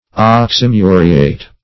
Search Result for " oxymuriate" : The Collaborative International Dictionary of English v.0.48: Oxymuriate \Ox`y*mu"ri*ate\, n. (Old Chem.)